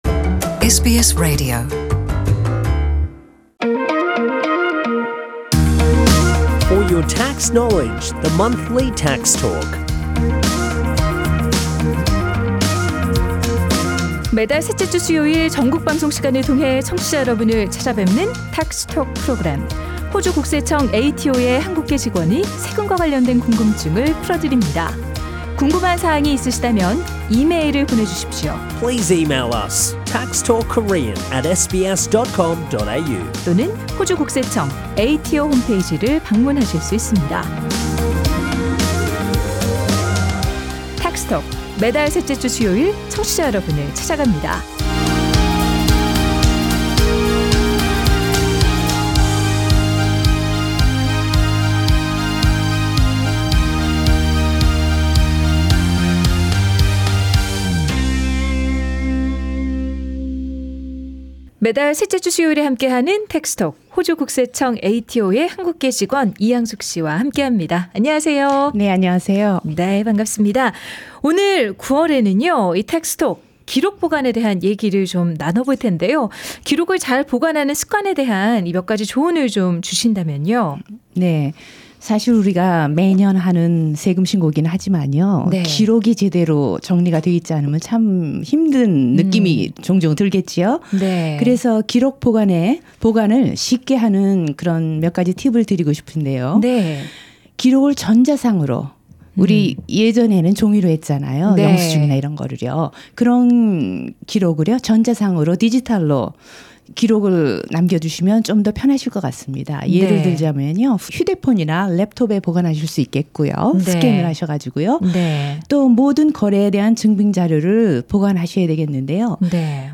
ATO’s employee with Korean background joins SBS radio studio to explain about Record keeping.